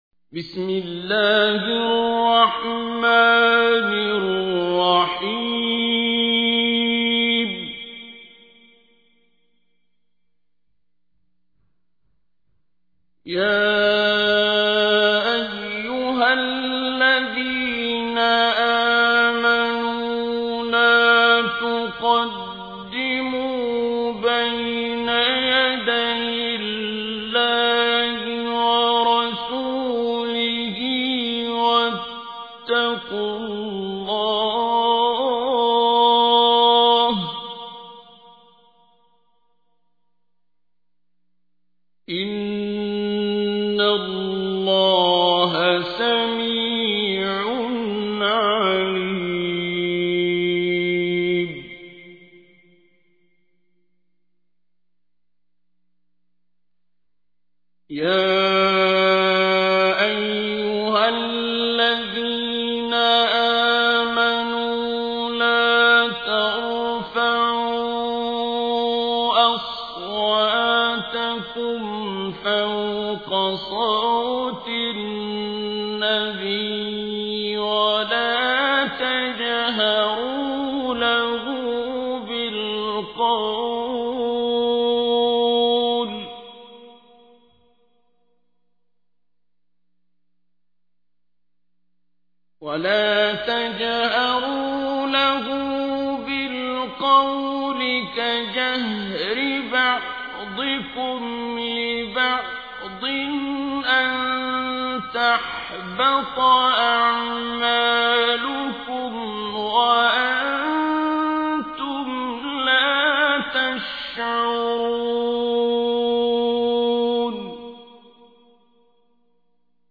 تحميل : 49. سورة الحجرات / القارئ عبد الباسط عبد الصمد / القرآن الكريم / موقع يا حسين